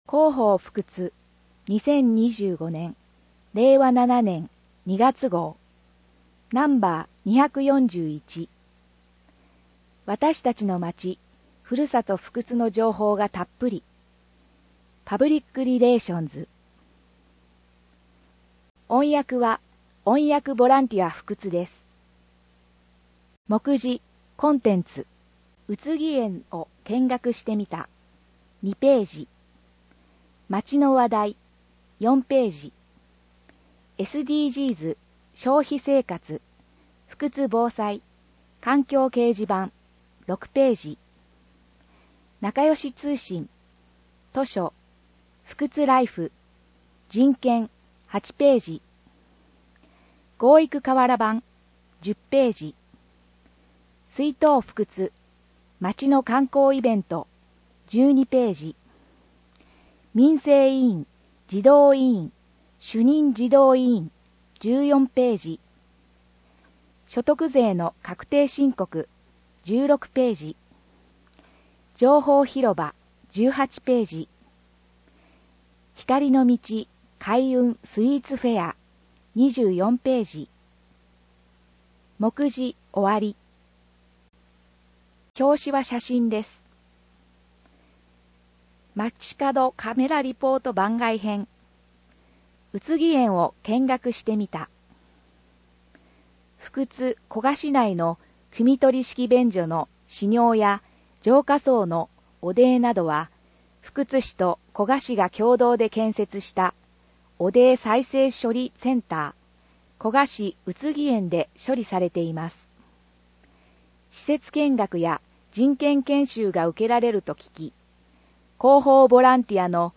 音訳ボランティアふくつの皆さんが、毎号、広報ふくつを音訳してくれています。